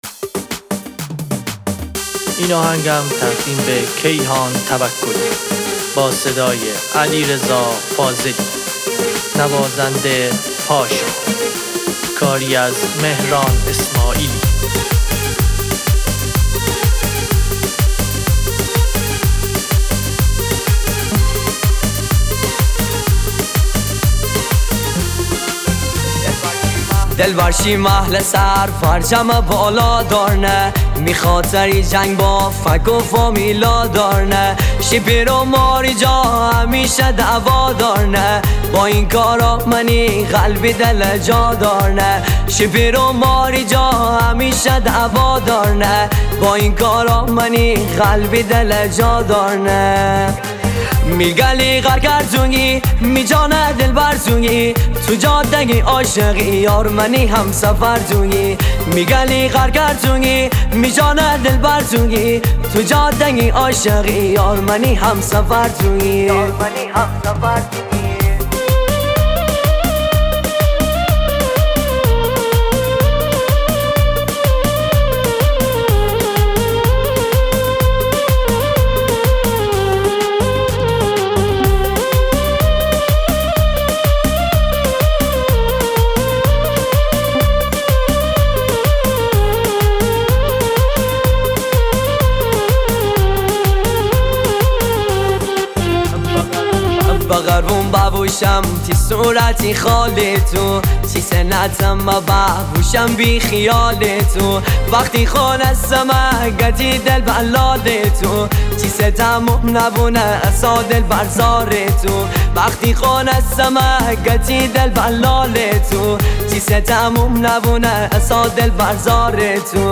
دانلود آهنگ مازندرانی